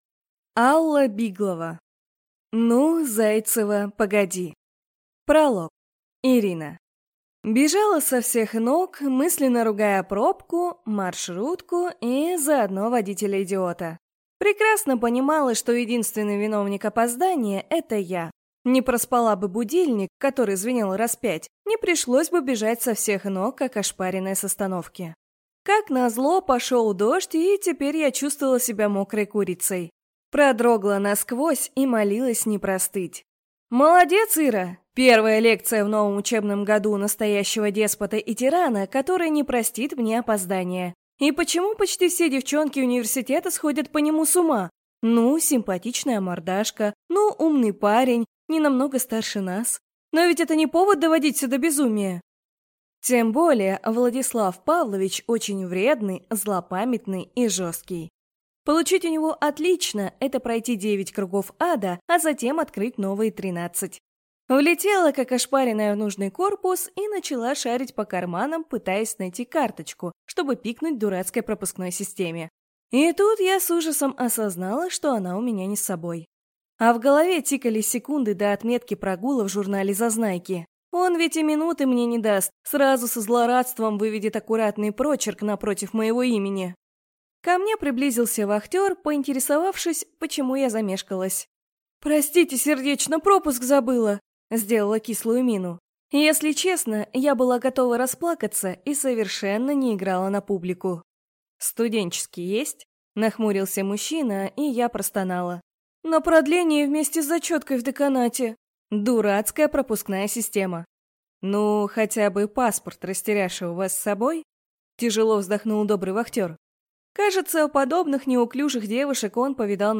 Аудиокнига Ну, Зайцева, погоди!